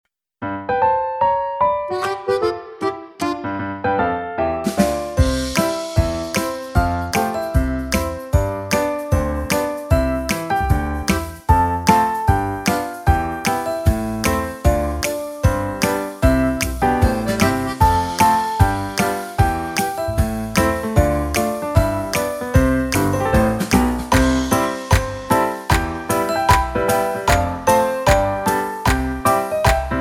utwór w wersji instrumentalnej